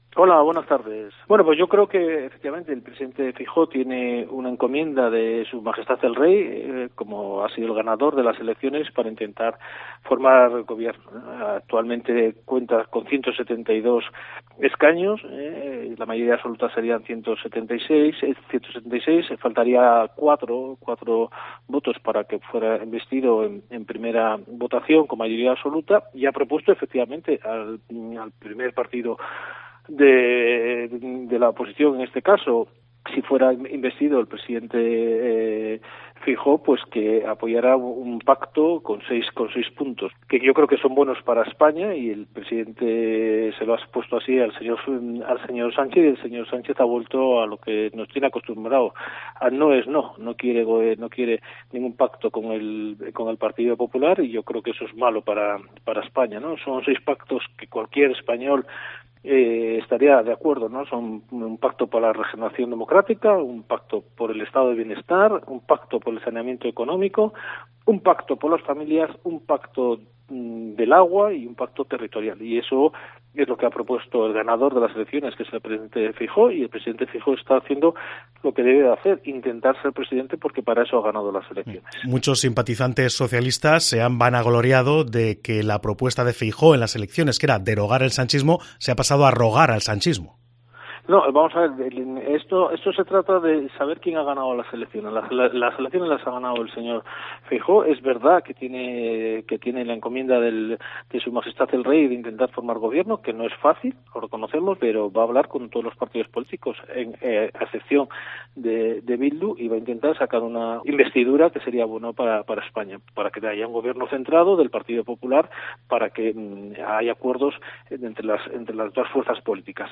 Escucha en Cope a Félix de las Cuevas, diputado nacional del PP, sobre el "no" de Sánchez a Feijóo